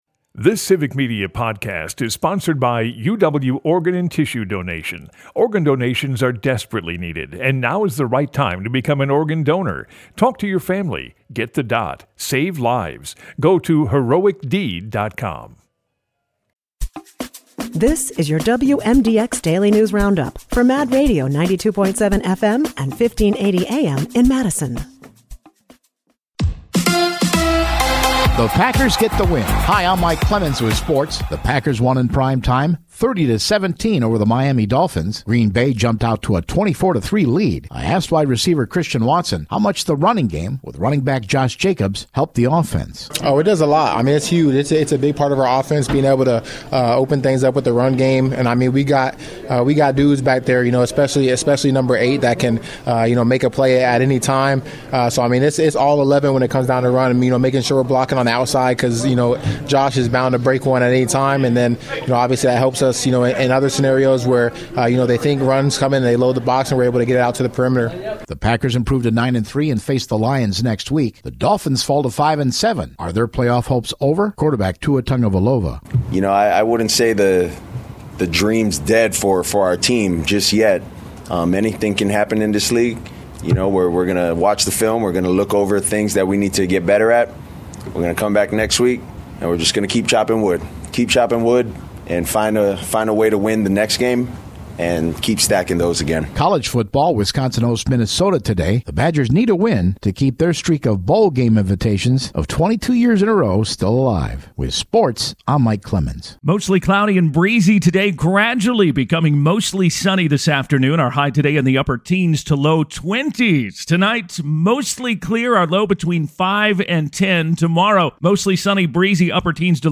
The WMDX Mad Radio Daily News Roundup has your state and local news, weather, and sports for Madison, delivered as a podcast every weekday at 9 a.m. Stay on top of your local news and tune in to your community!